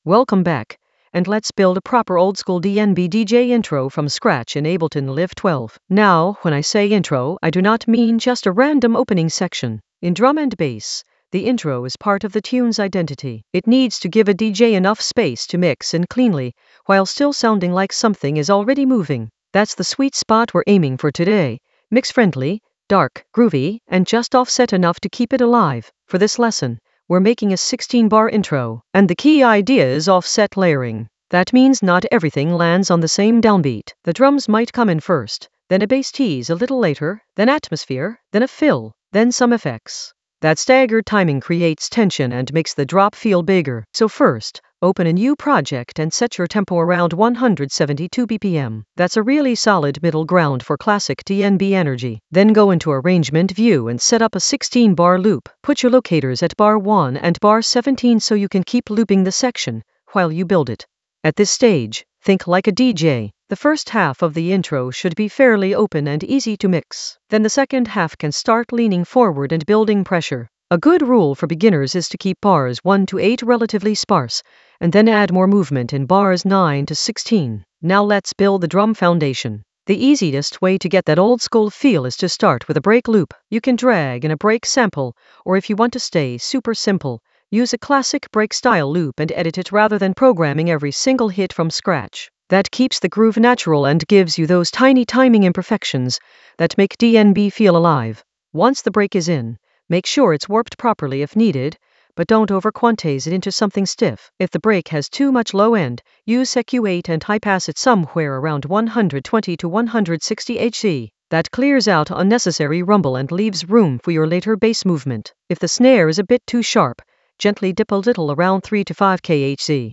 Narrated lesson audio
The voice track includes the tutorial plus extra teacher commentary.
offset-oldskool-dnb-dj-intro-from-scratch-in-ableton-live-12-beginner-groove.mp3